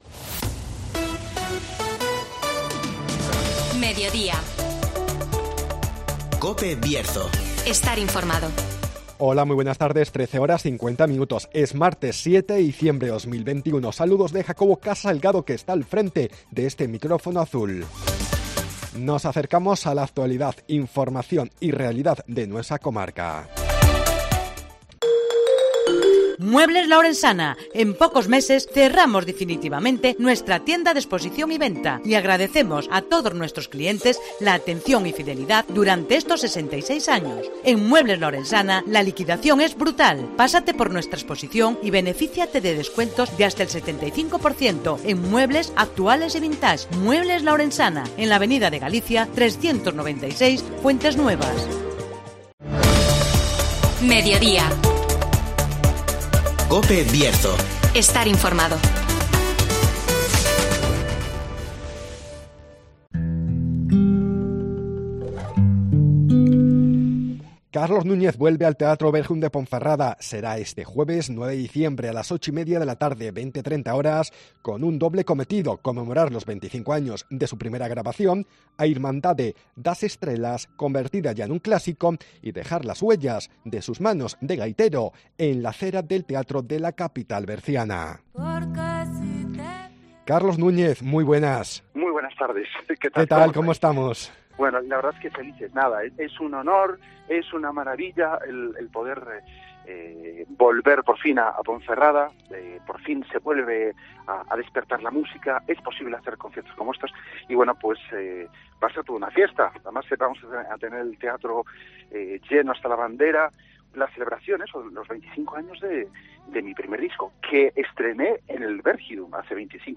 Entrevista a Carlos Núñez que llega este jueves, 9 de diciembre al Teatro Bergidum de Ponferrada.